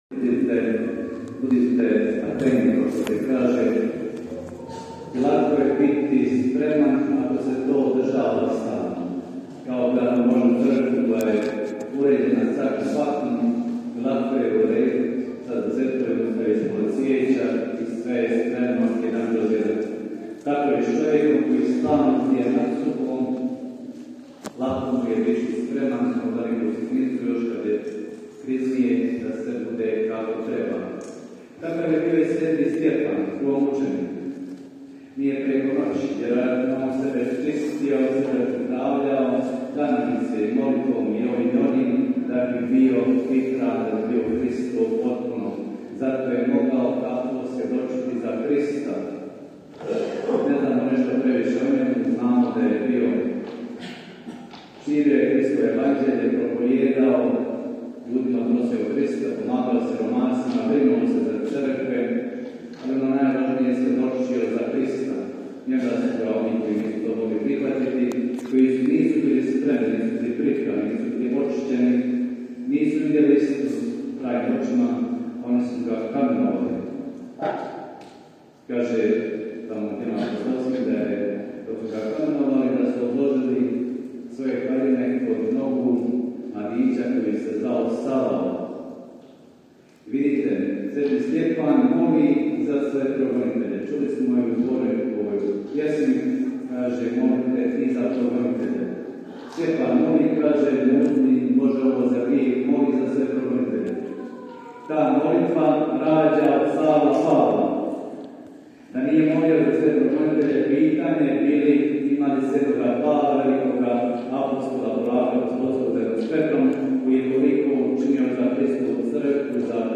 Danas je, po tradiciji, svetkovina svetog Stjepana proslavljena svečanom svetom misom u područnoj crkvi u Stipanićima kojoj je zaštitnik sv. Stjepan.